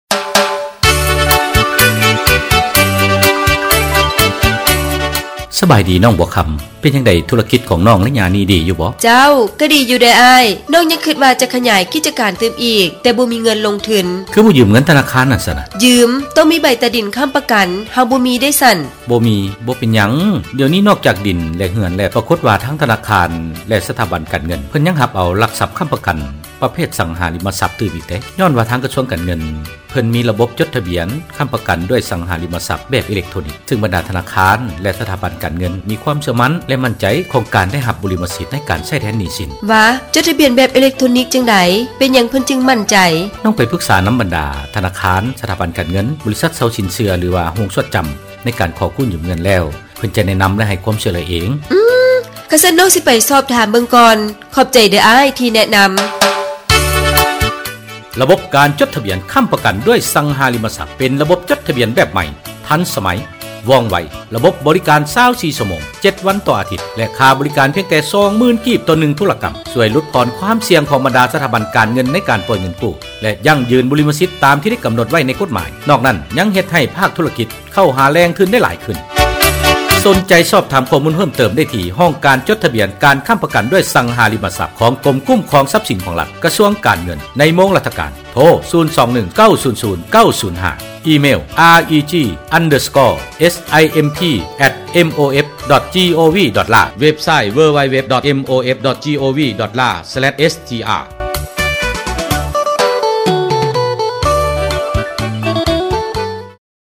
spot_radio_4.mp3